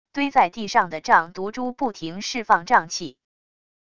堆在地上的瘴毒珠不停释放瘴气wav音频